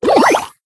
surge_reload_01.ogg